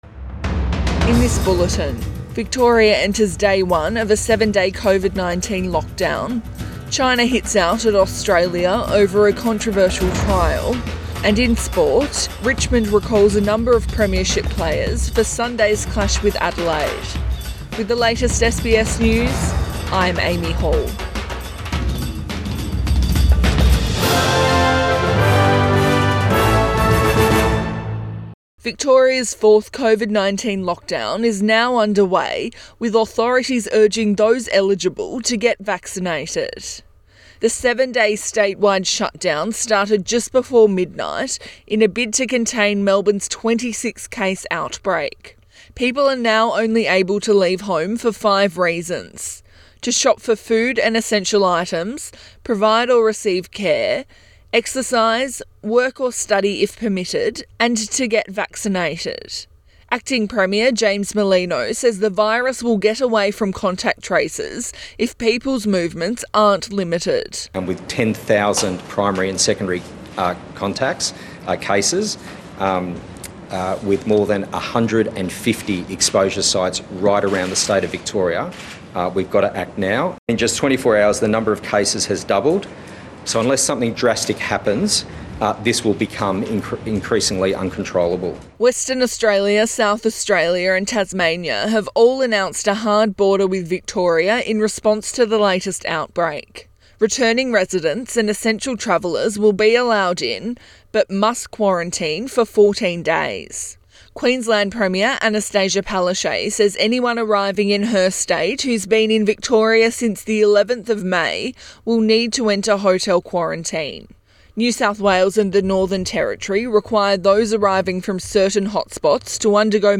AM bulletin 28 May 2021